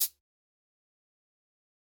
Nothing Compares Shaker.wav